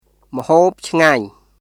[ムホープ・チガニュ　mhoːp cʰŋaɲ]